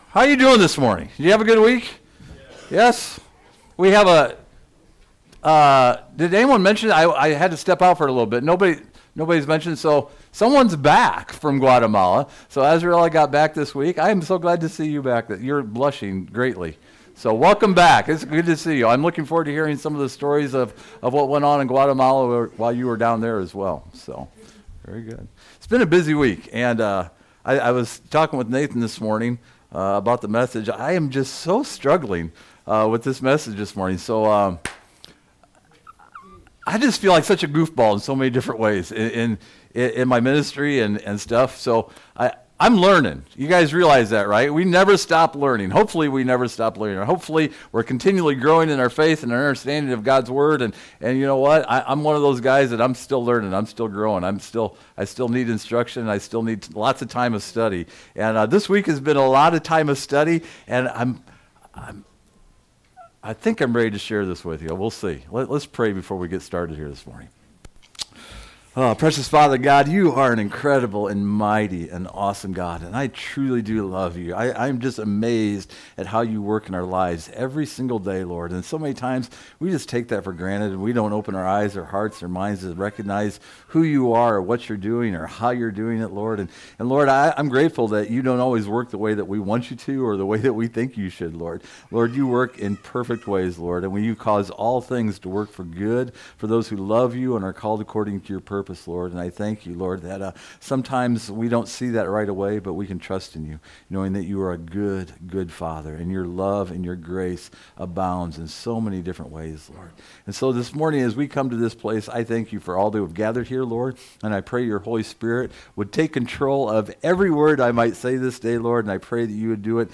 In this sermon we recognize that part of the very nature of God is his mercy or compassion. Then we explore David from the Old Testament and his need for compassion and mercy.
Service Type: Sunday Morning